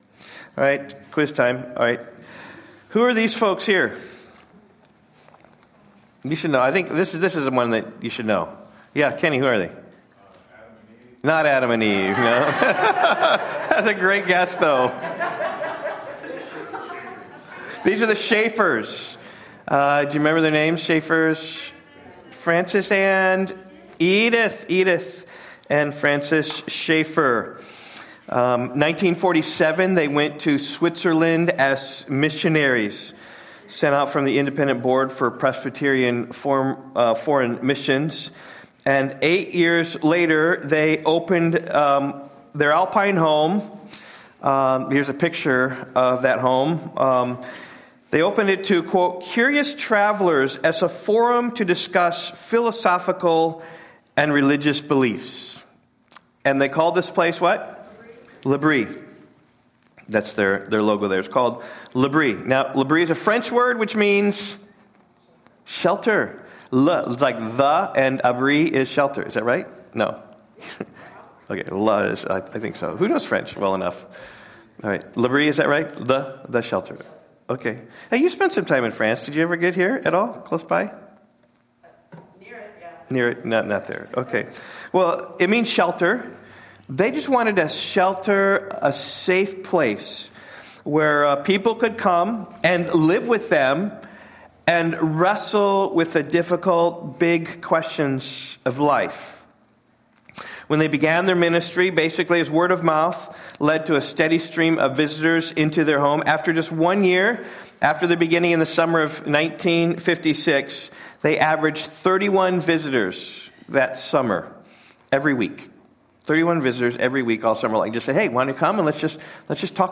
Jesus Walks On Water - John 6:16-35 Rock Valley Bible Church Sermons podcast To give you the best possible experience, this site uses cookies.